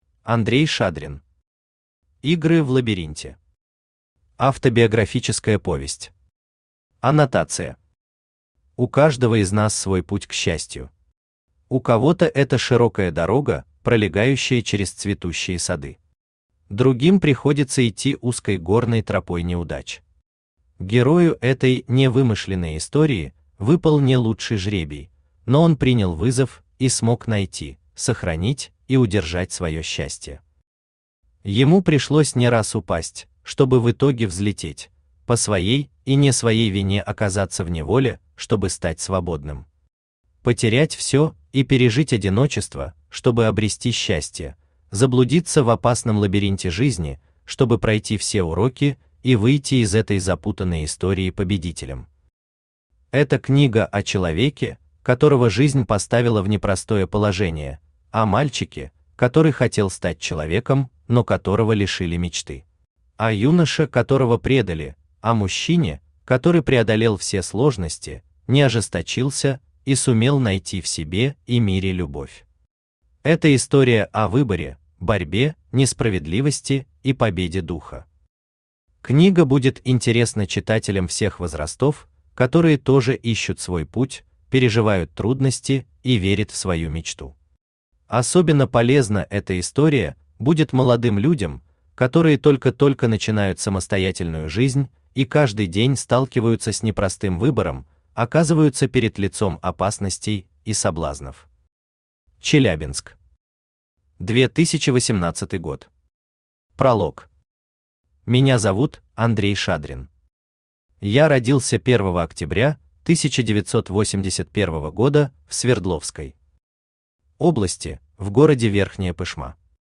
Аудиокнига Игры в лабиринте | Библиотека аудиокниг
Aудиокнига Игры в лабиринте Автор Андрей Владимирович Шадрин Читает аудиокнигу Авточтец ЛитРес.